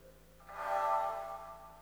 FOG FX-L.wav